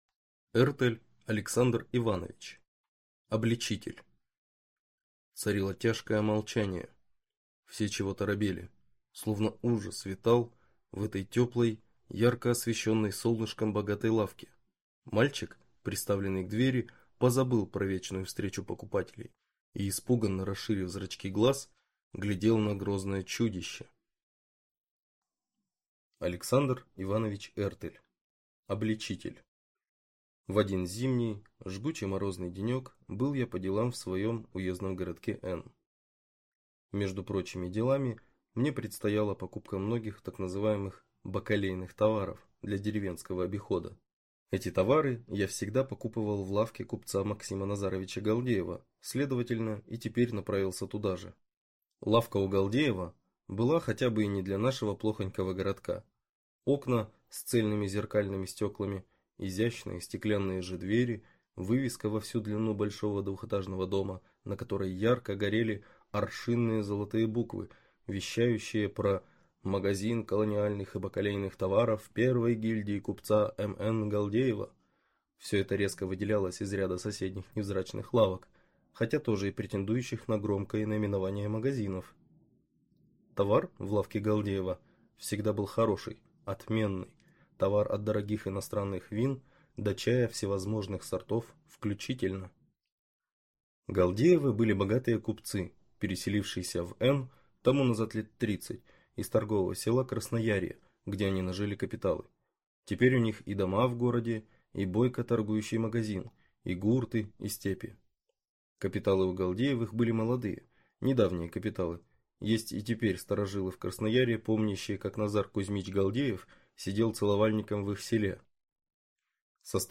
Аудиокнига Обличитель | Библиотека аудиокниг